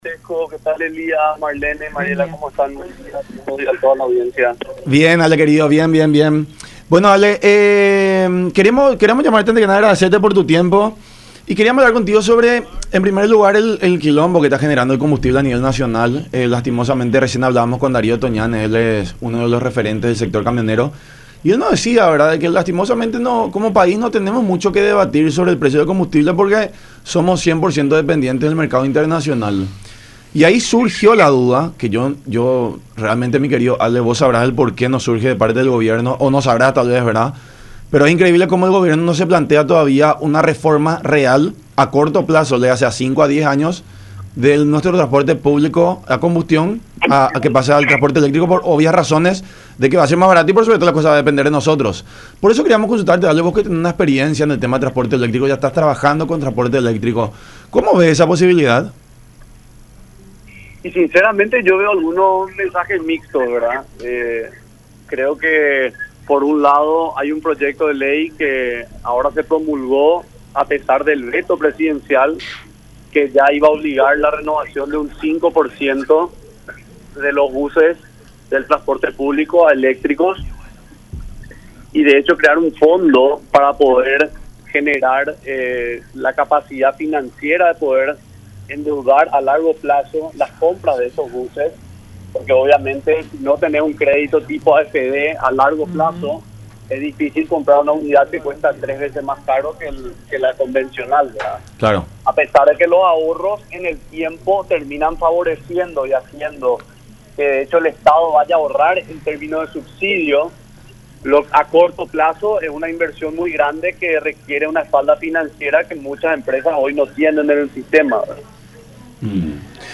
en diálogo con La Unión Hace La Fuerza por Unión TV y radio La Unión